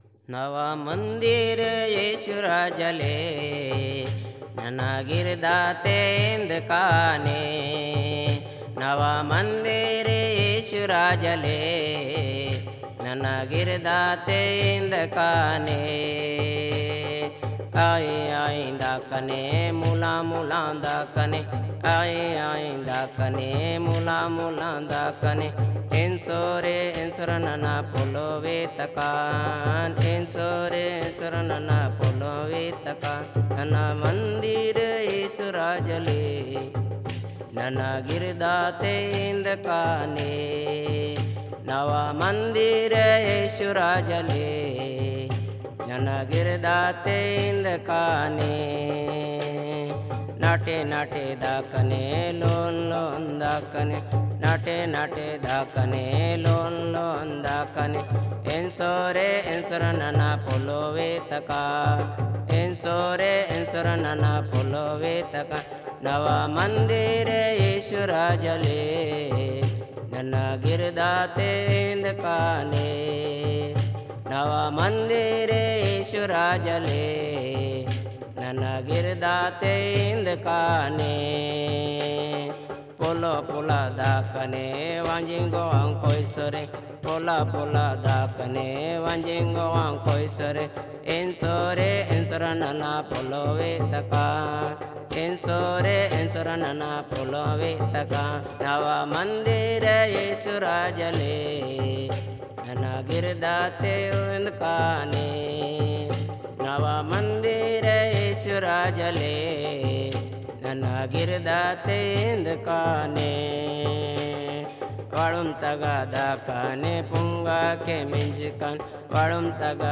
Madia Gond devotional songs praise songs worship songs